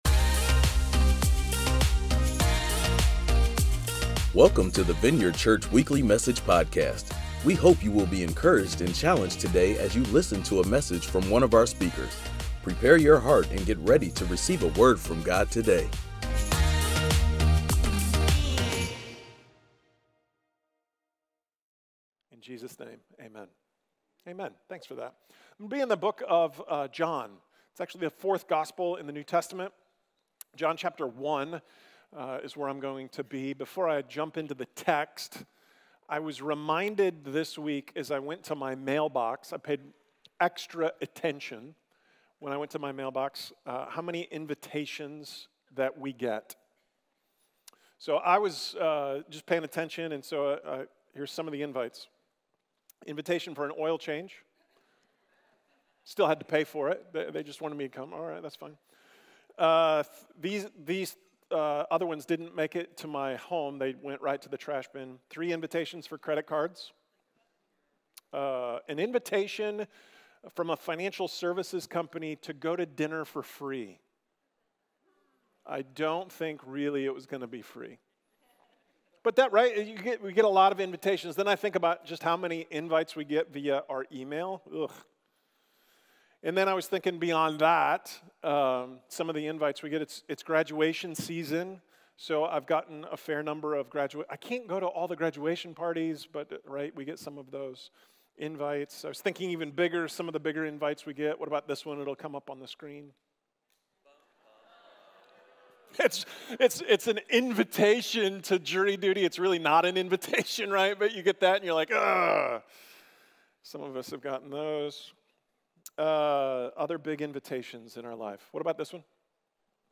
During this message, we took a look at John chapter one to see the the story of Jesus inviting Andrew to follow him.